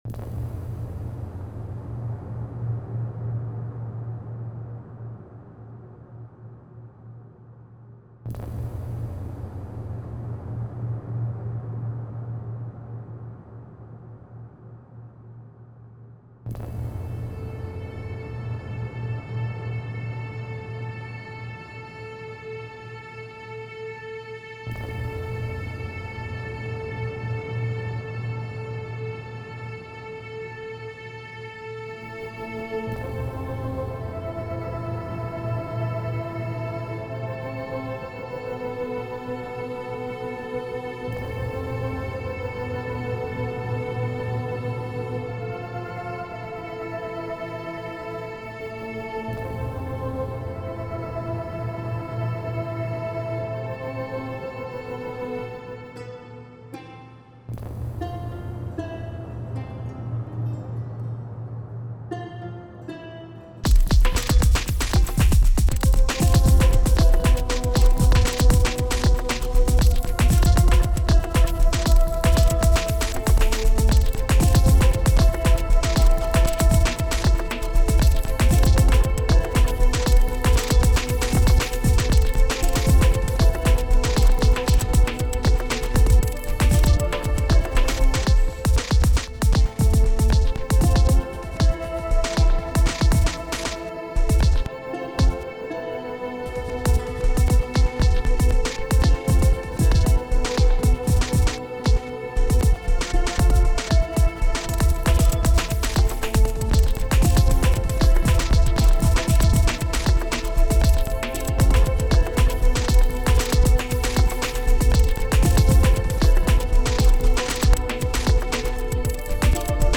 Nice and smooth, without being boring at all.